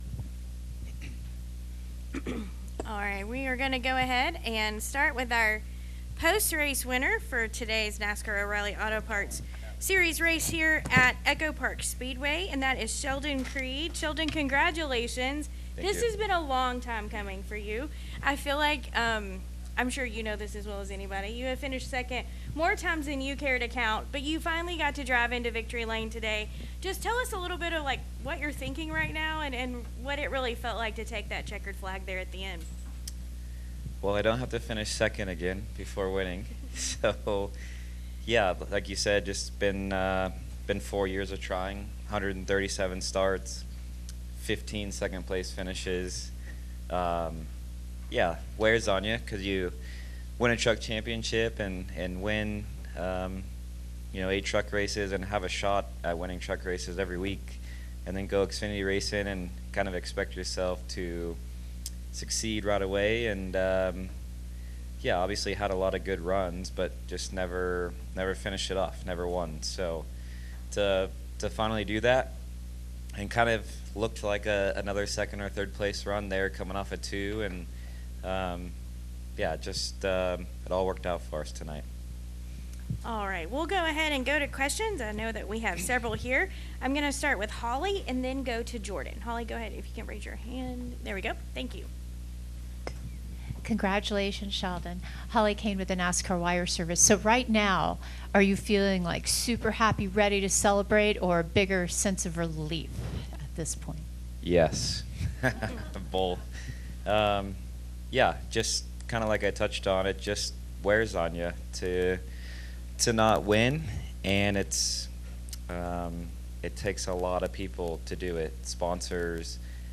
Interview: NOAPS Sheldon Creed (No. 00 Haas Factory Team Chevrolet) –